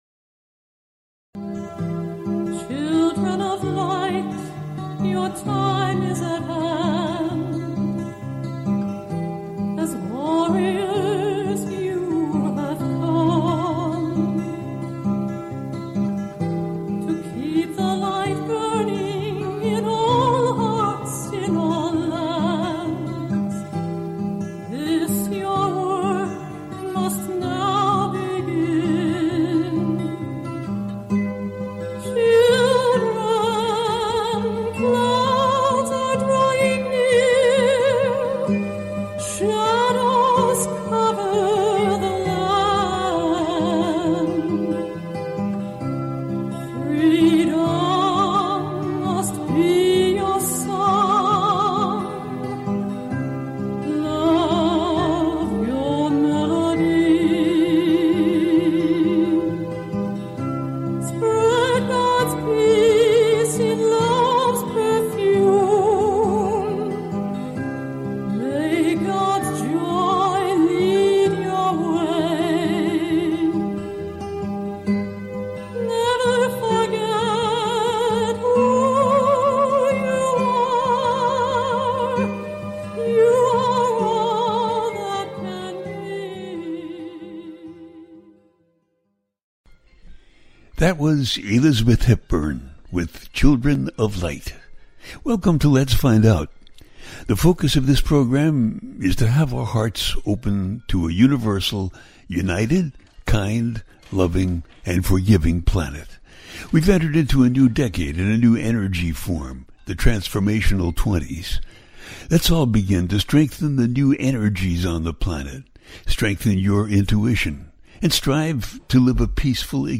Talk Show Episode
The listener can call in to ask a question on the air.
Each show ends with a guided meditation.